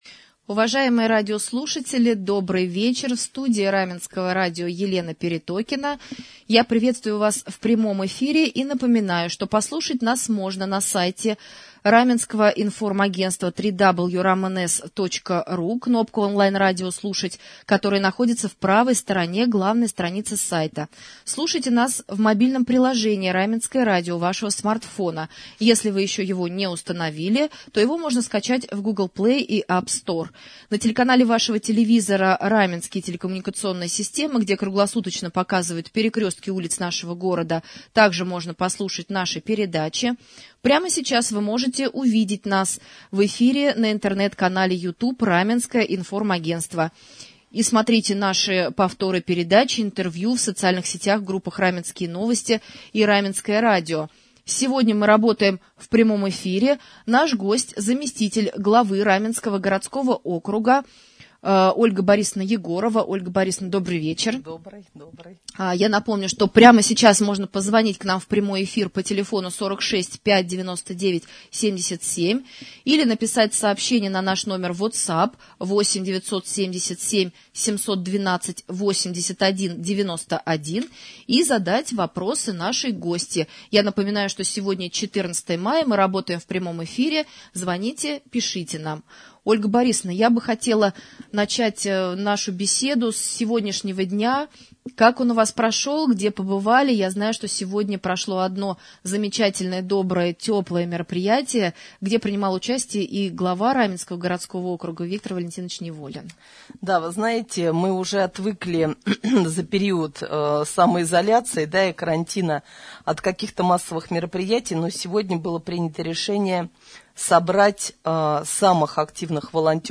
Заместитель главы администрации Раменского городского округа стала гостем прямого эфира на Раменском радио 14 мая 2020 г.